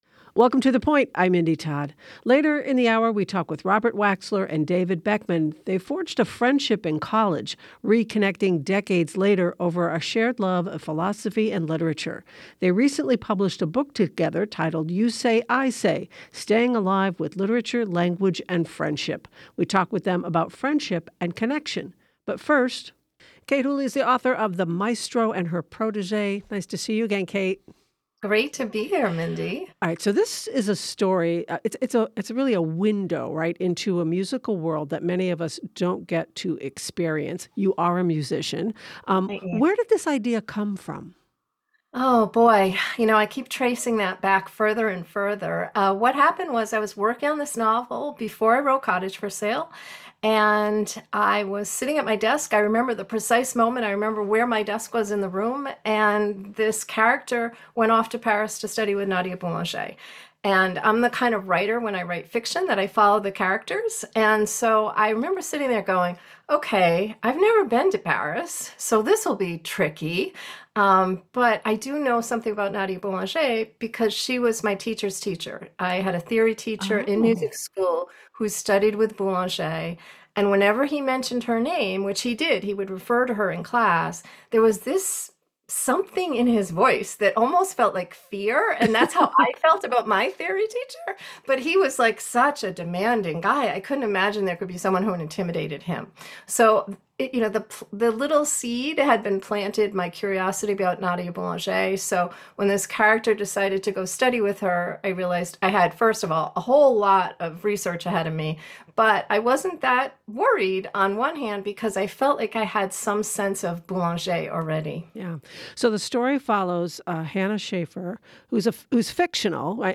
Regional News Roundup.
WCAI's award-winning public affairs program.